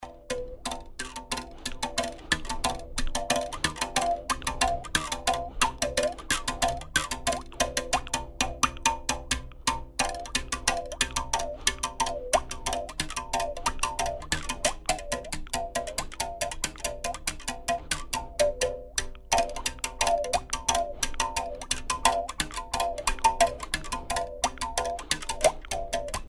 Improvisation sur arc à bouche Gahakan
arc en bouche gahakan
Casamance (Sénégal)